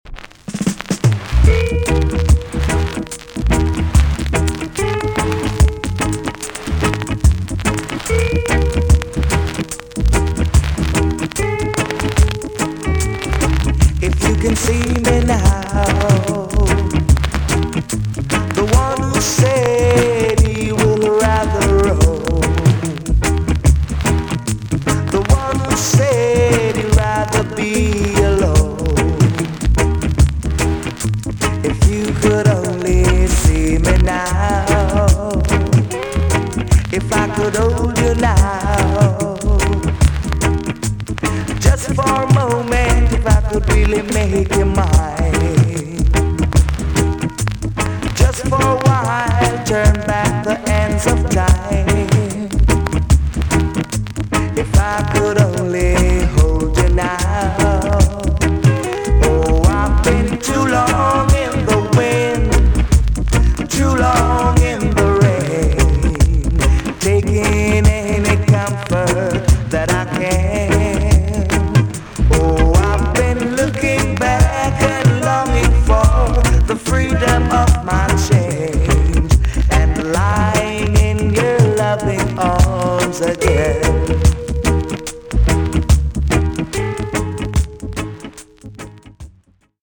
TOP >REGGAE & ROOTS
VG ok チリノイズが入ります。
NICE SWEET VOCAL TUNE!!